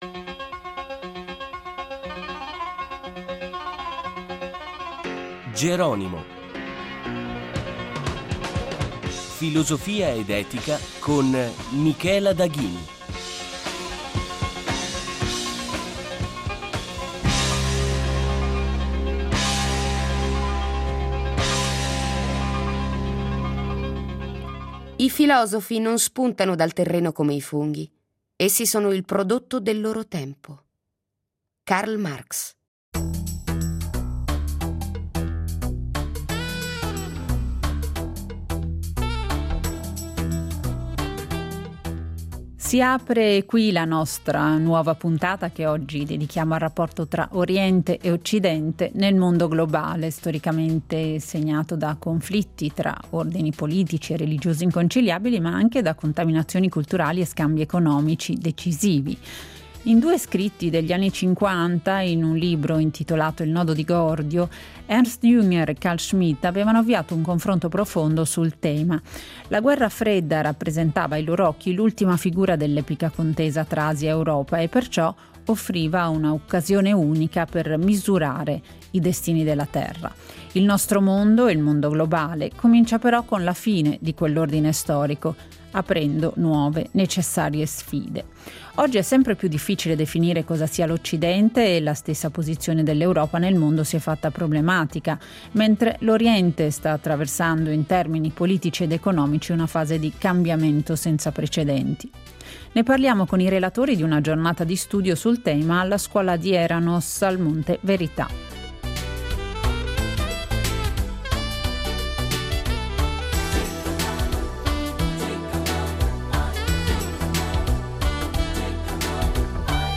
Ospiti del programma i filosofi